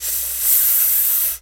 pgs/Assets/Audio/Animal_Impersonations/snake_hiss_08.wav at master
snake_hiss_08.wav